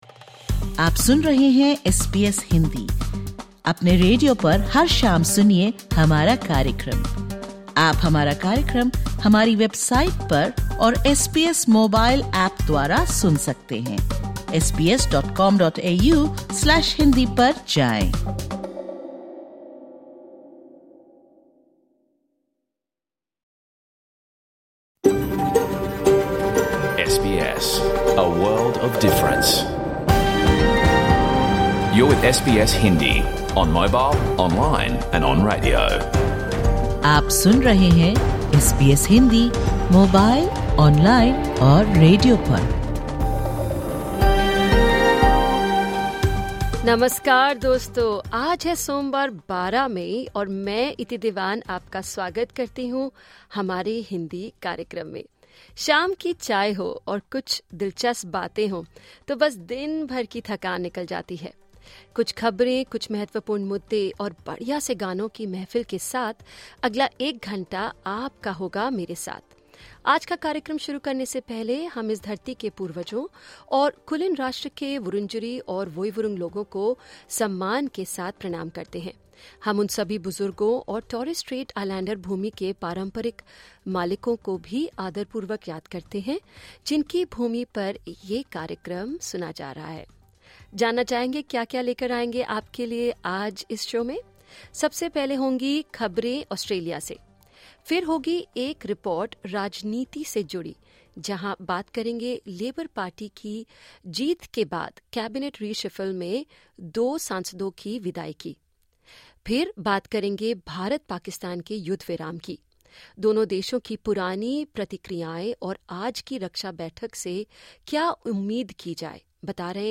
In this edition of the SBS Hindi radio program, tune in for an exclusive interview with celebrity Indian chef Sanjeev Kapoor as he shares his favourite spots in Australia and insights on the future of cooking. On International Nurses Day, Australian nurses speak candidly about the challenges they face on the frontlines. We also hear expert analysis on the stability of the India-Pakistan ceasefire, and cover the political shake-up in Canberra following Labor’s landslide election victory.